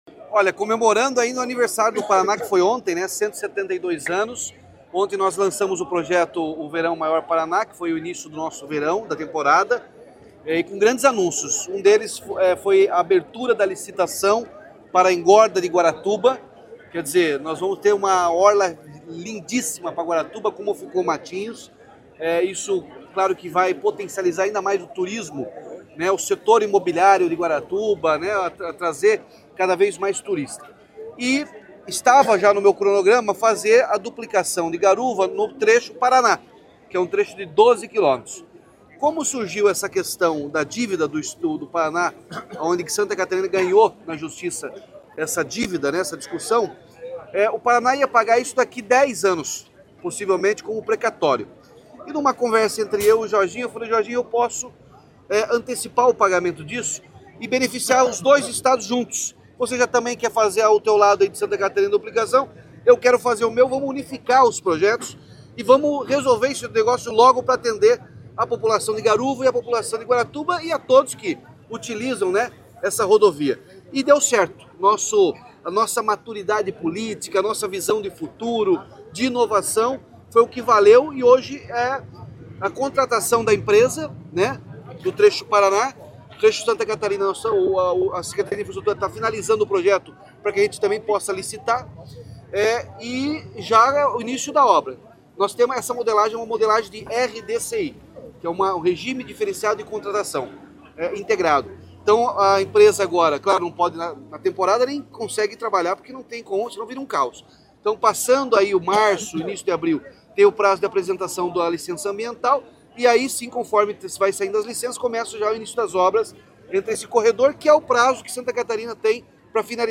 Sonora do governador Ratinho Junior sobre a autorização da duplicação da PR-412, em Guaratuba